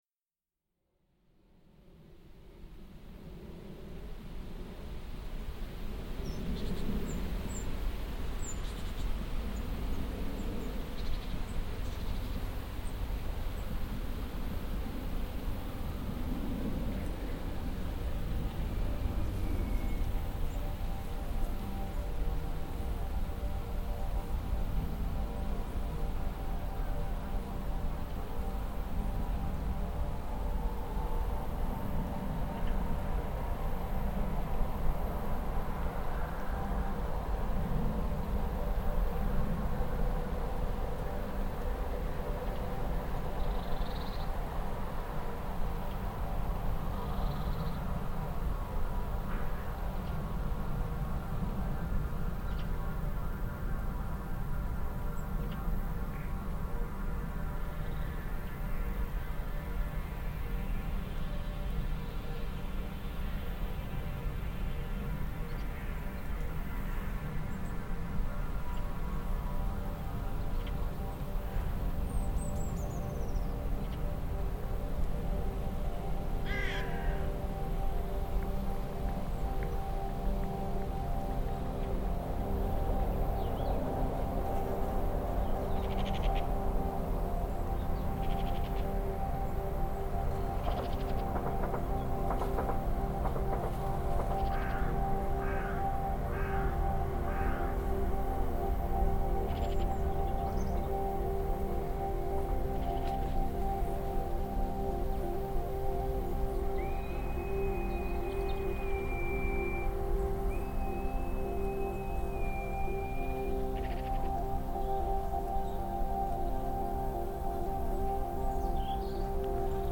The title refers to the ancient ritual defining the boundaries where grazing rights exist. The ritual moves from place to place, varying but effectively the same, sometimes overpowering the contemporary soundscape sometimes succumbing to it.